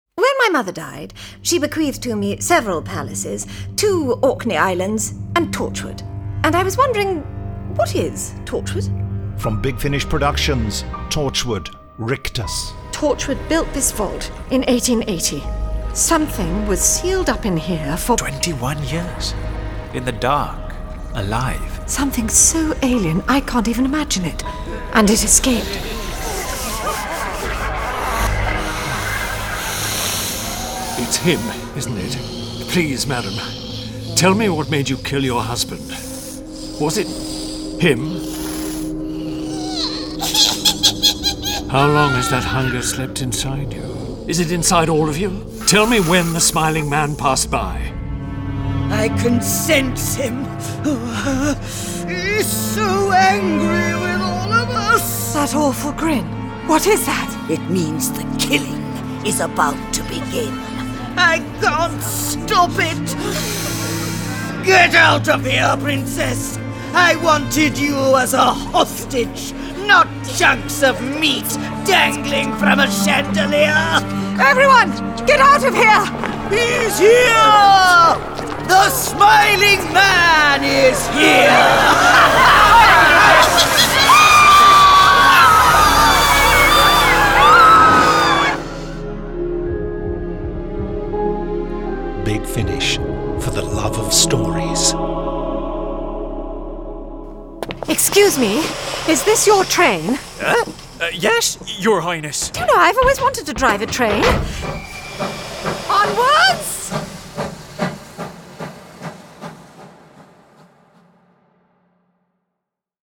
Award-winning, full-cast original audio dramas
Torchwood: Rictus Released March 2025 Written by James Goss Starring Fenella Woolgar This release contains adult material and may not be suitable for younger listeners.